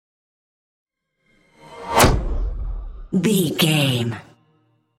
Pass by fast speed flash
Sound Effects
Fast
futuristic
intense
sci fi
car